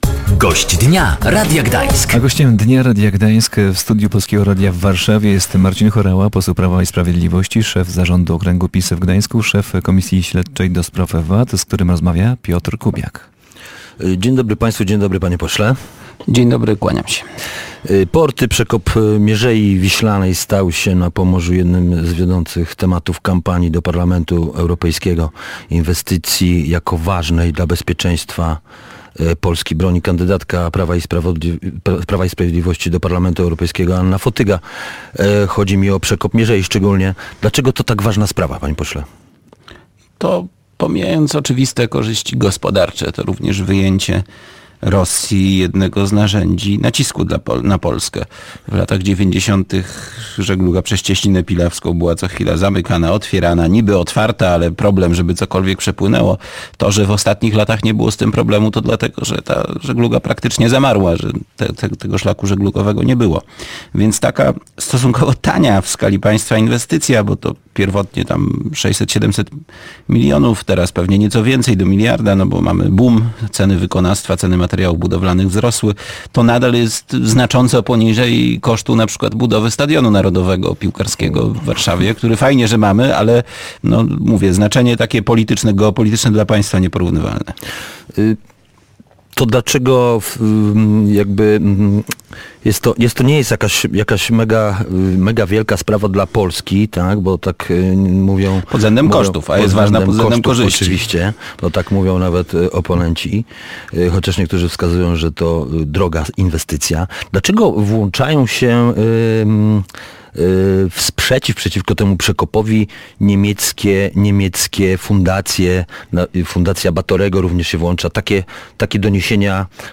Dlaczego to tak ważne przedsięwzięcie? Między innymi o tym mówił Marcin Horała. Poseł Prawa i Sprawiedliwości, szef zarządu okręgu PiS w Gdańsku i szef komisji śledczej ds. VAT był Gościem Dnia Radia Gdańsk.